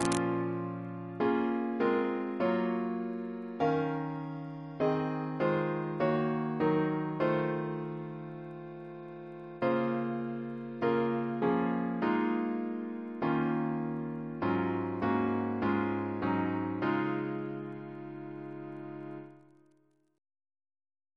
Double chant in B♭ minor Composer